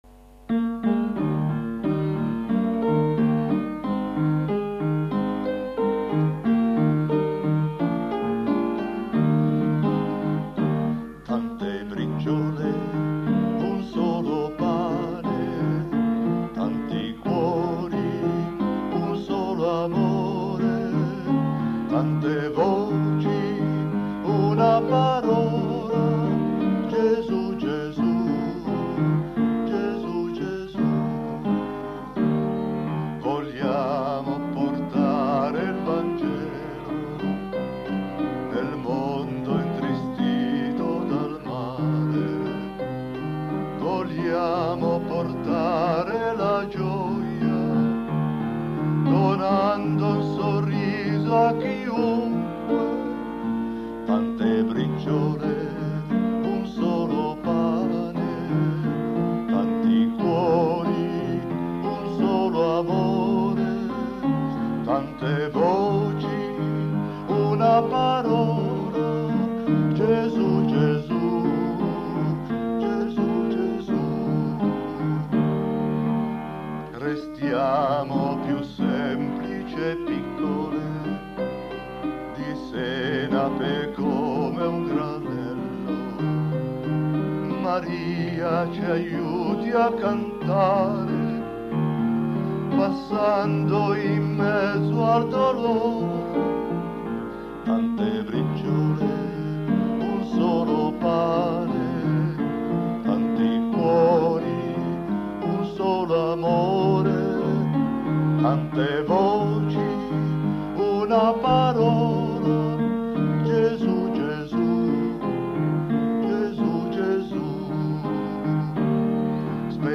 Inno
L’inno delle briciole è il frutto dell’ispirazione di Mons. Angelo Comastri che ha scritto il testo e della musica del Maestro Agostino Dodero